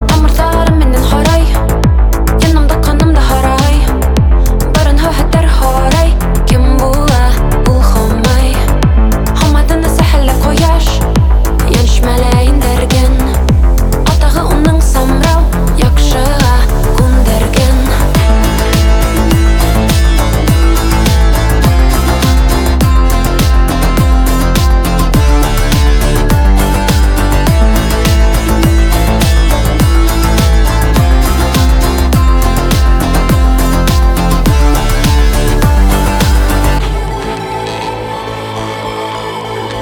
Танцевальные рингтоны
фолк , этнические , башкирские